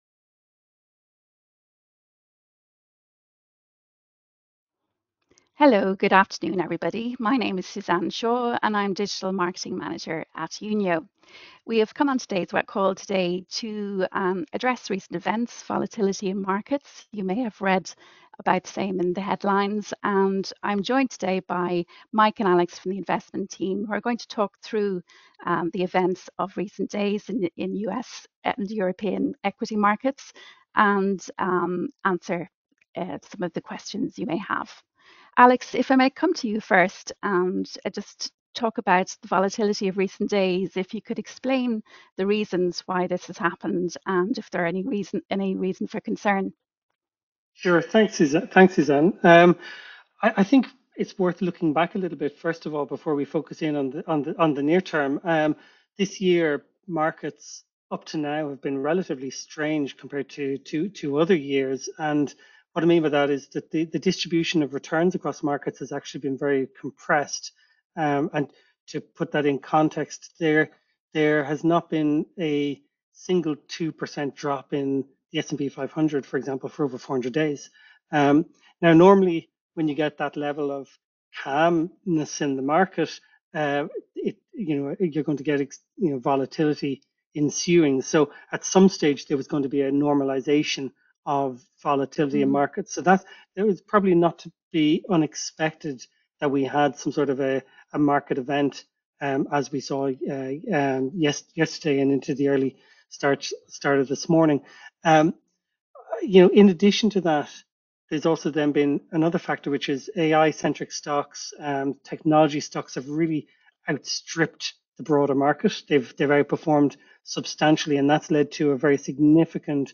Unio-Client-Call-_What-does-market-stress-mean-for-the-economy_August-2024.mp3